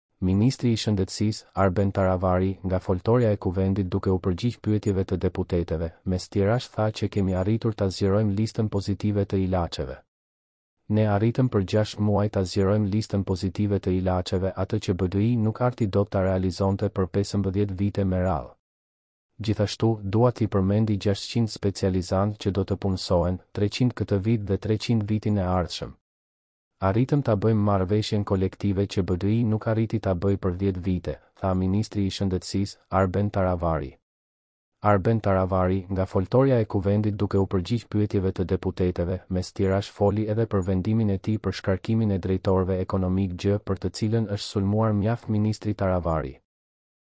Ministri i Shëndetësisë, Arben Taravari, nga foltorja e Kuvendit duke u përgjigj pyetjeve të deputeteve, mes tjerash tha që kemi arritur ta zgjerojmë listën pozitive të ilaçeve.